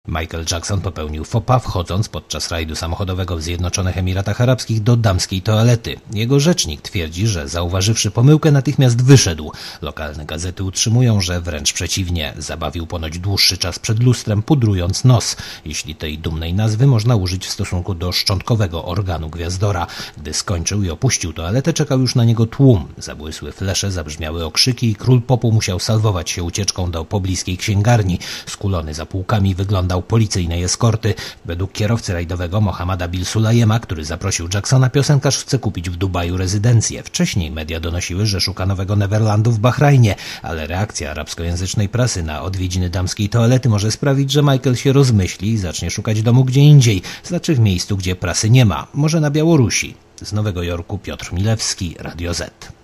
Relacja korespondenta Radia ZET